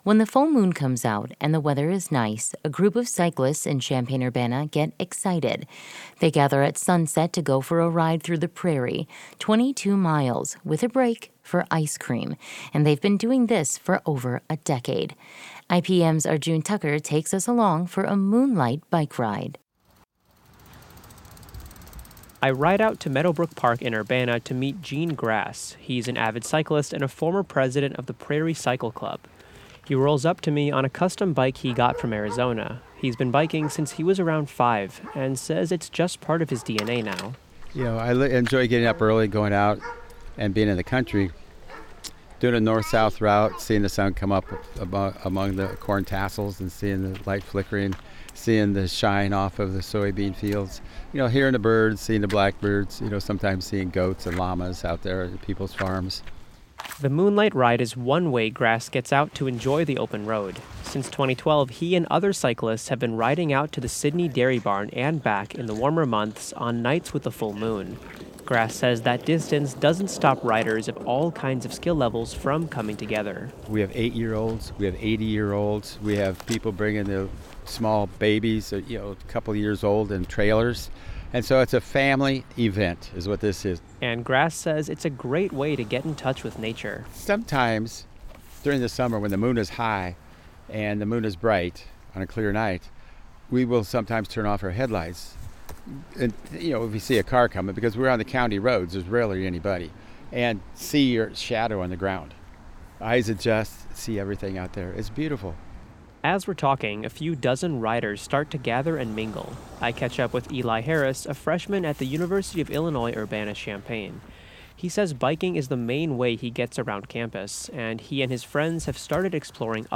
The main sounds we hear throughout the ride are chirping crickets chirping and loose gravel crunching under our tires.